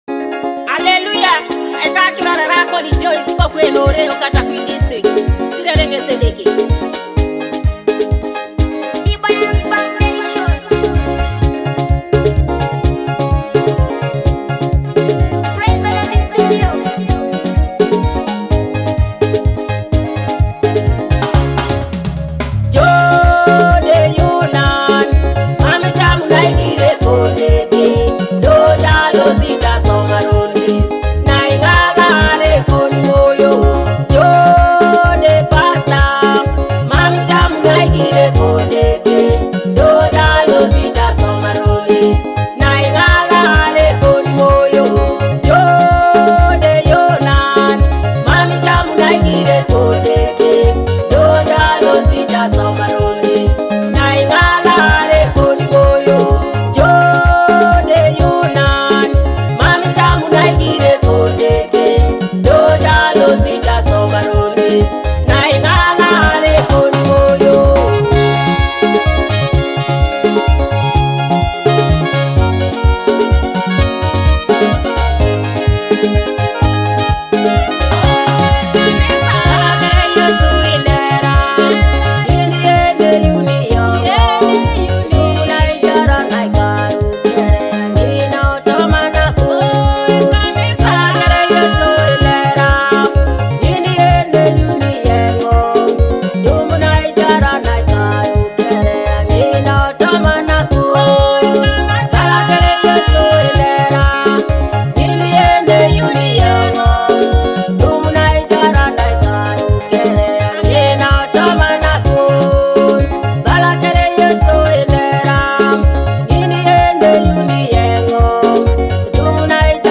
a powerful gospel anthem